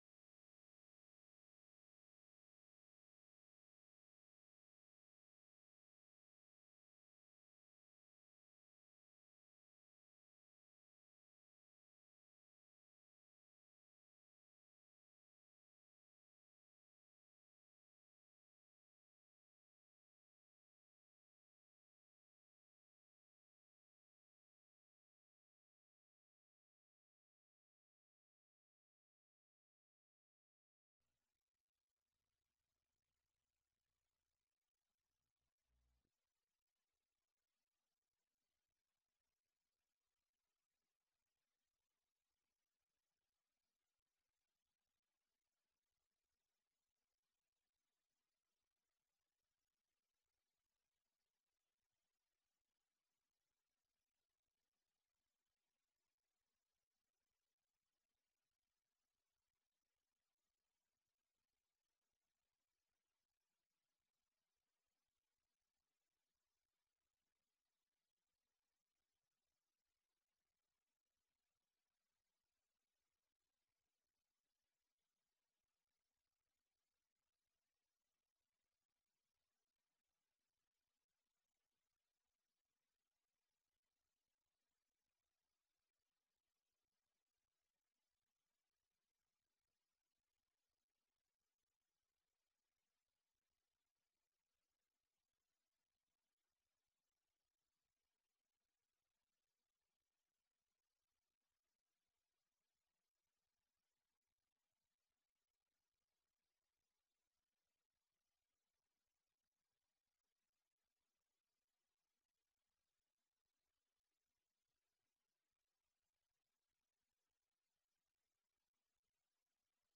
تاريخ النشر ٢٧ ربيع الأول ١٤٤٠ هـ المكان: المسجد الحرام الشيخ